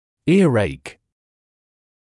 [‘ɪəreɪk][‘иарэйк]боль в ухе, оталгия